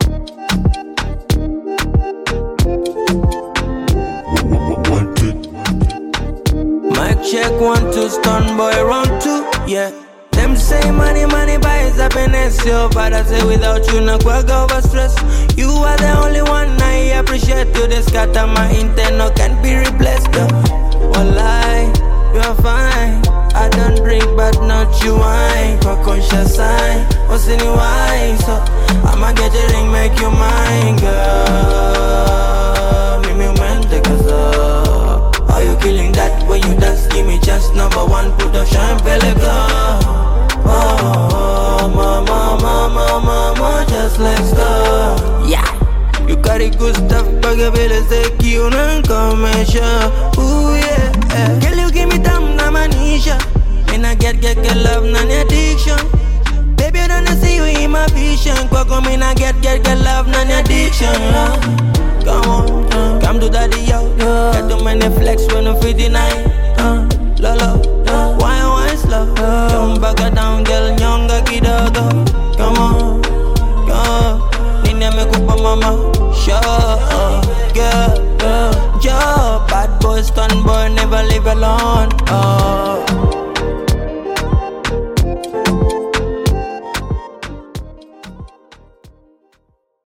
Bongo Flava You may also like